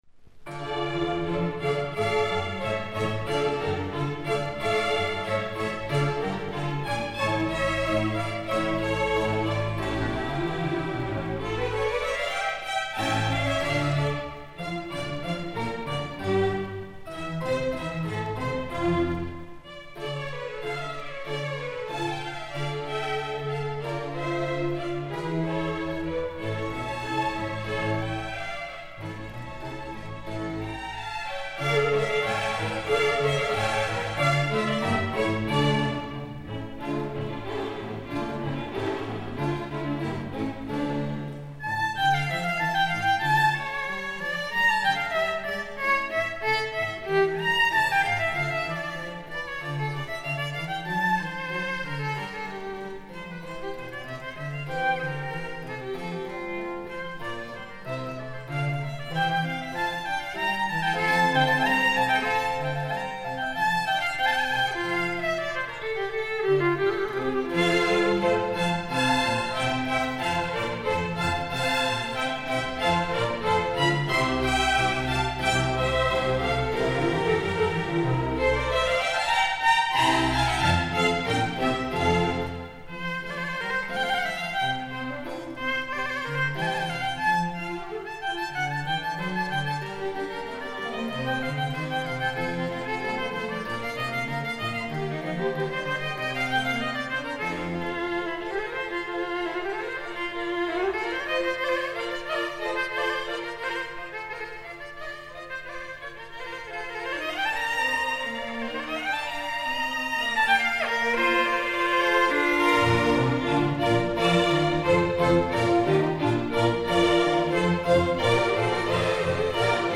These sympathetic strings, as they were called, added to the depth of sound of the instrument. They made a ‘silvery’ sound and have a clear tone.
He played a Gasparo da Salò viola and a Capicchioni viola d’amore.
The London Chamber Orchestra
Recorded in 1950